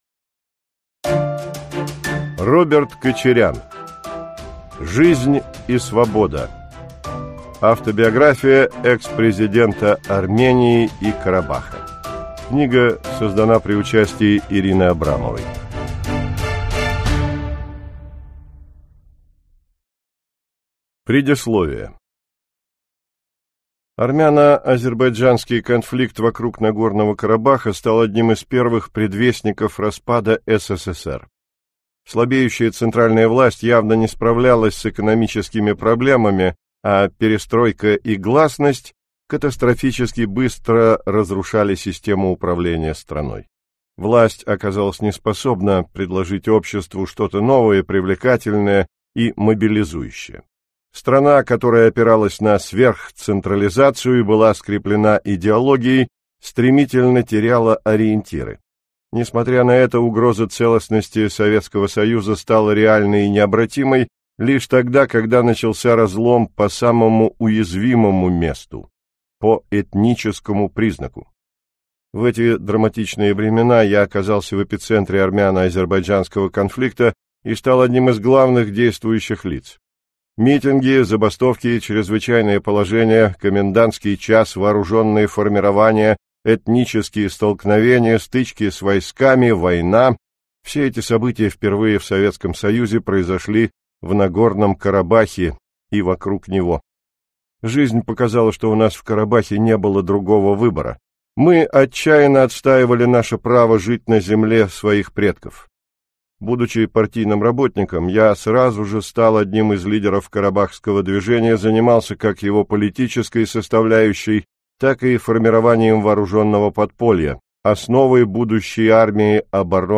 Аудиокнига Жизнь и свобода. Автобиография экс-президента Армении и Карабаха | Библиотека аудиокниг